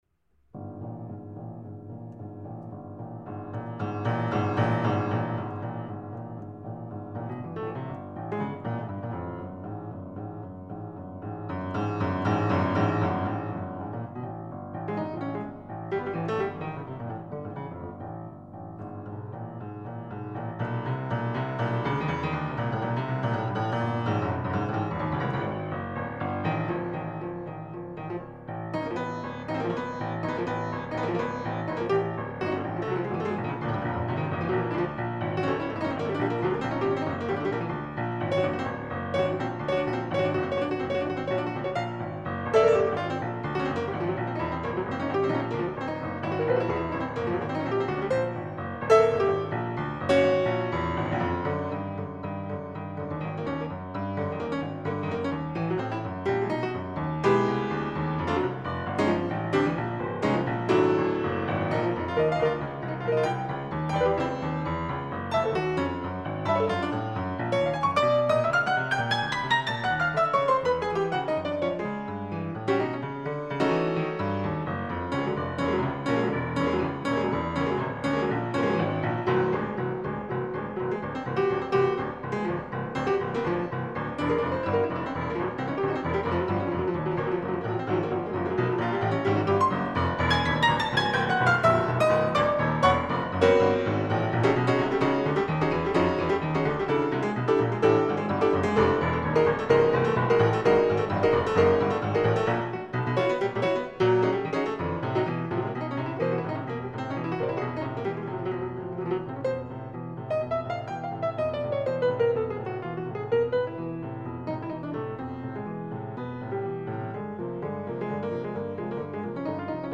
Lieu d'enregistrement : Nantes
piano solo